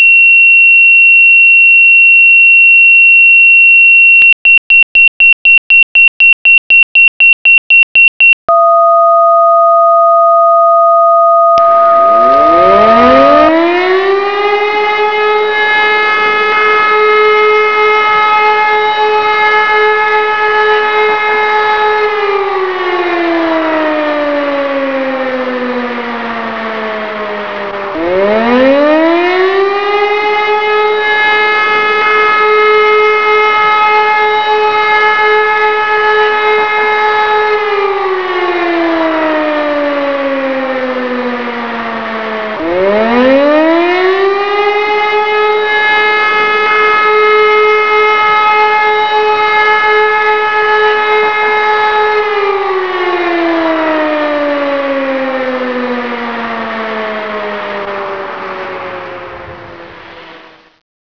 ALARM2.wav